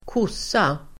Ladda ner uttalet
Uttal: [²k'os:a]